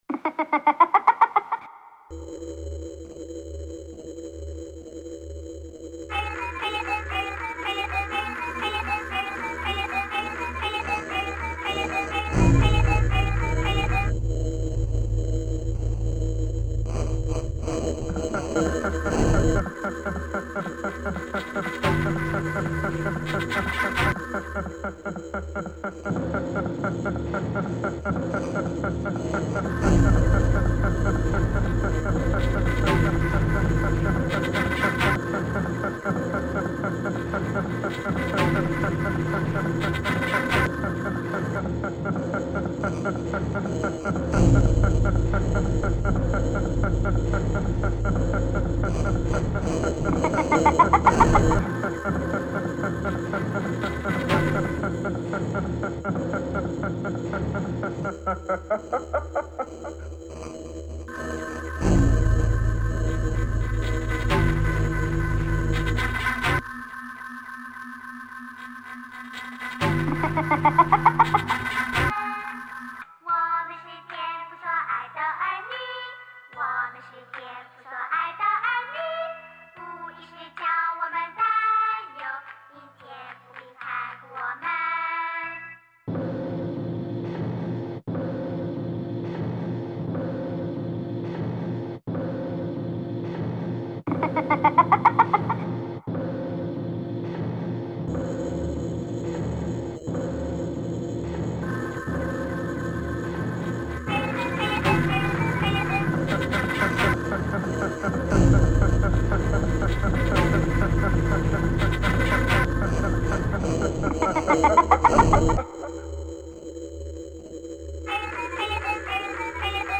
soundscapes